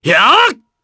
Clause-Vox_Attack4_jp.wav